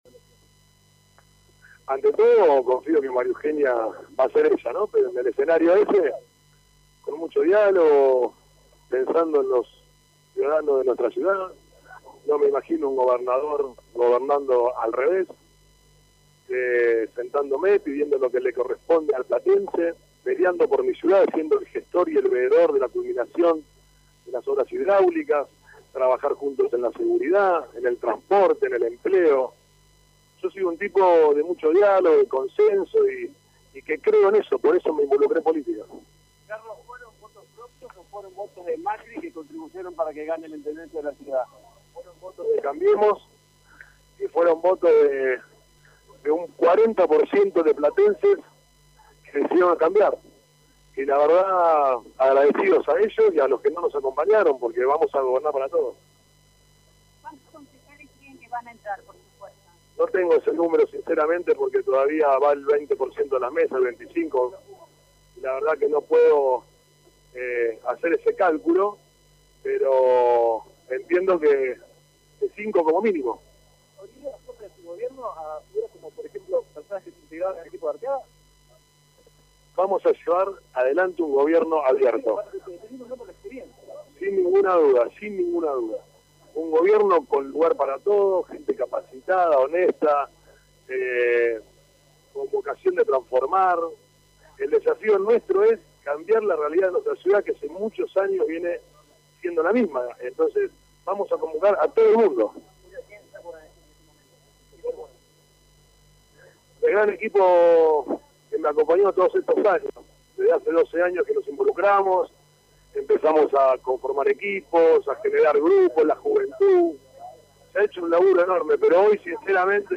Julio Garro, candidato a intendente por Cambiemos, en conferencia de prensa estimó un 5%, como mínimo, de diferencia con el actual intendente Pablo Bruera.